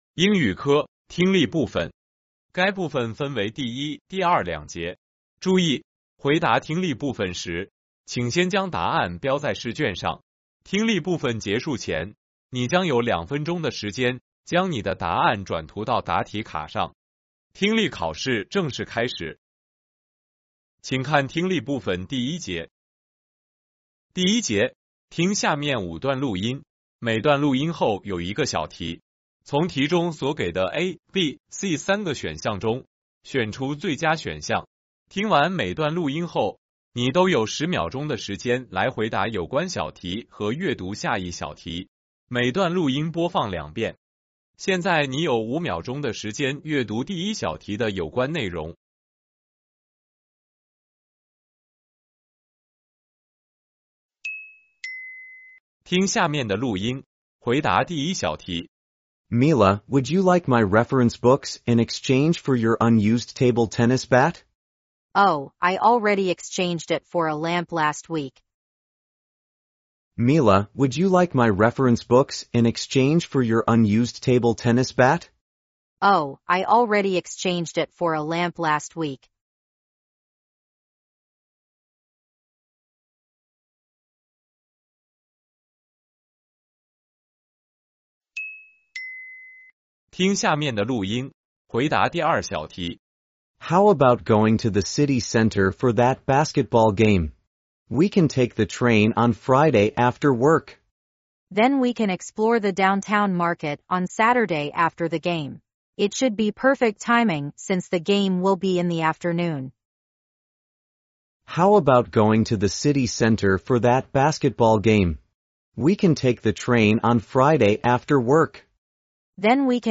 重庆八中2026届高三下学期入学考试英语听力.mp3